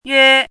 “约”读音
国际音标：jyɛ˥;/jɑu˥
yuē.mp3